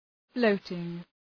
Shkrimi fonetik {‘fləʋtıŋ}